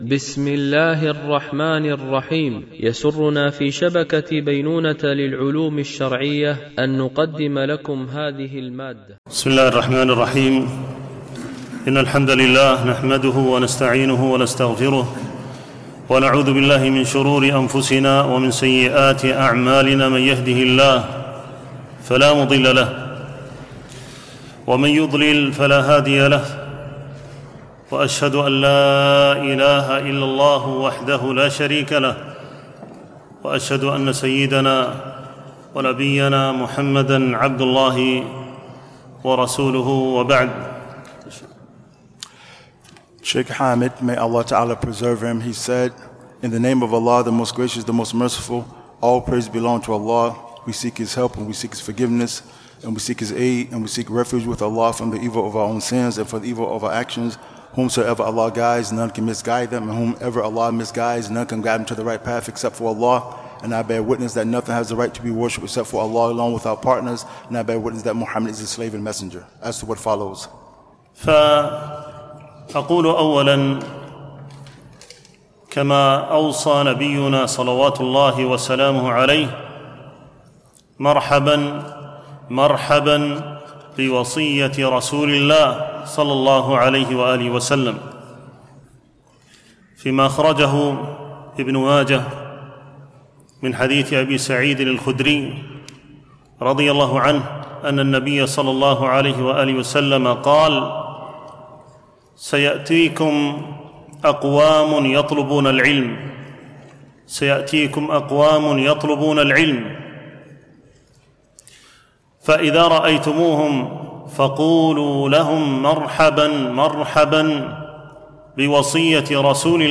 بمسجد أم المؤمنين عائشة رضي الله عنها